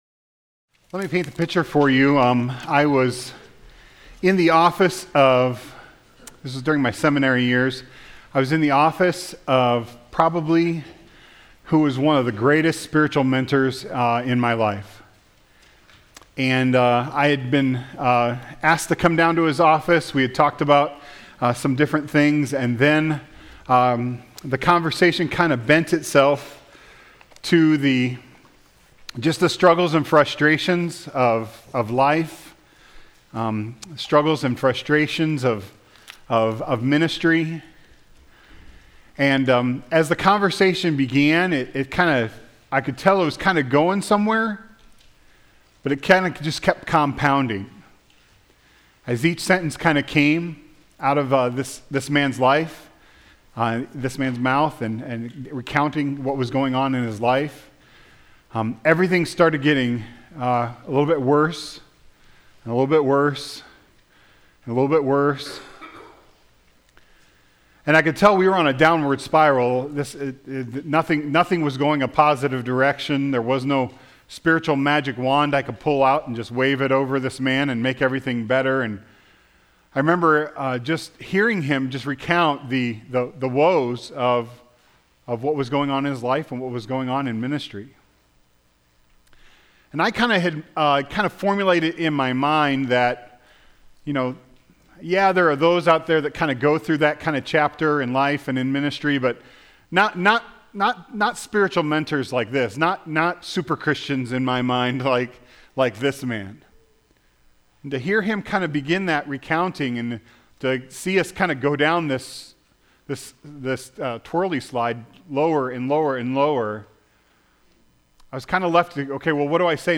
Sermons from First Baptist Church from the Psalter; God's Songs for Life.